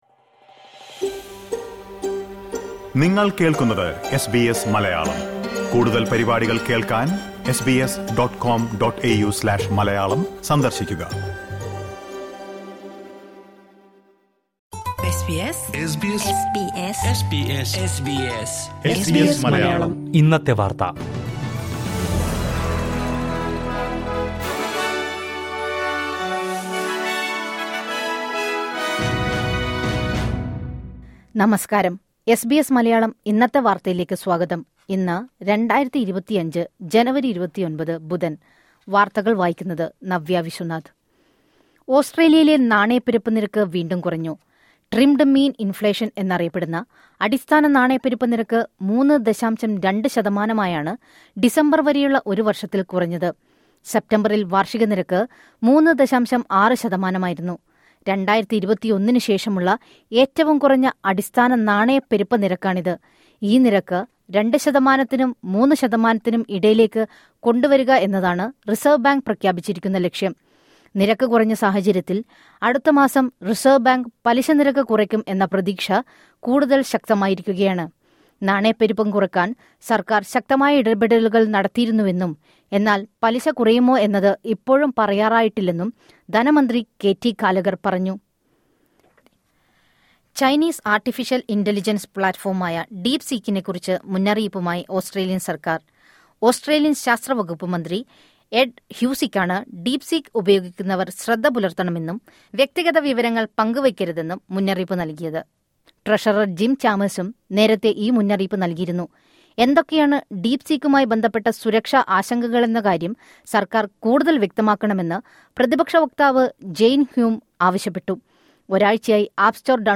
2025 ജനുവരി 29ലെ ഓസ്‌ട്രേലിയയിലെ ഏറ്റവും പ്രധാന വാര്‍ത്തകള്‍ കേള്‍ക്കാം...